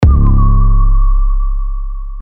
• Качество: 320, Stereo
без слов
короткие
электронные
эхо